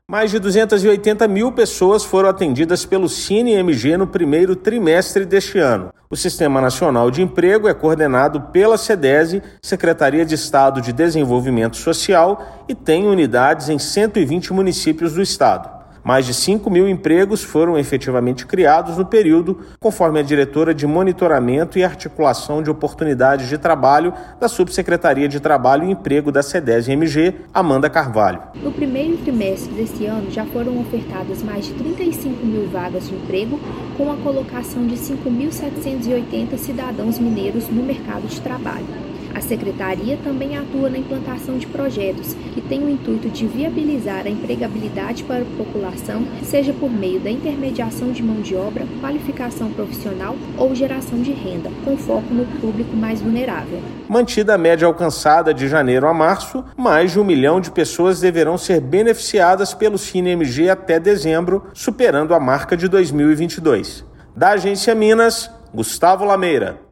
[RÁDIO] Sine de Minas Gerais atende mais de 280 mil trabalhadores no primeiro trimestre de 2023
Se média se mantiver ao longo do ano, mais de 1 milhão de pessoas serão beneficiadas até dezembro, superando a marca de 2022. Ouça matéria de rádio.